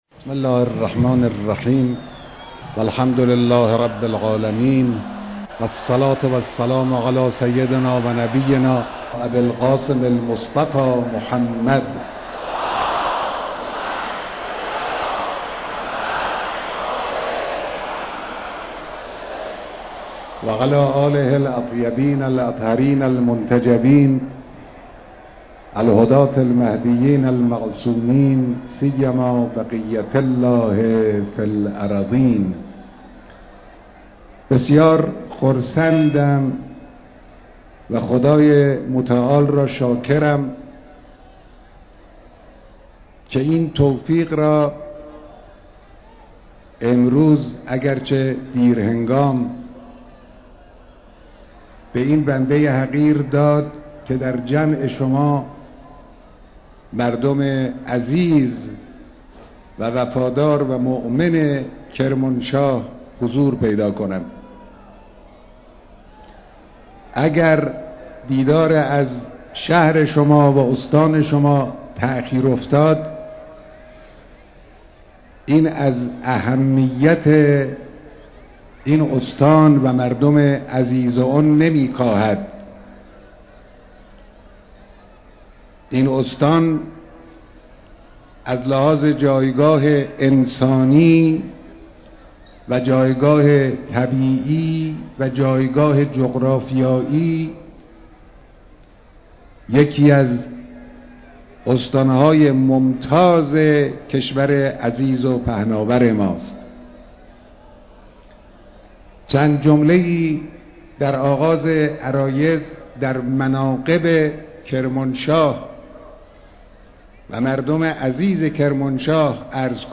بیانات مقام معظم رهبری در اجتماع پرشور مردم کرمانشاه
بیانات در جمع مردم کرمانشاه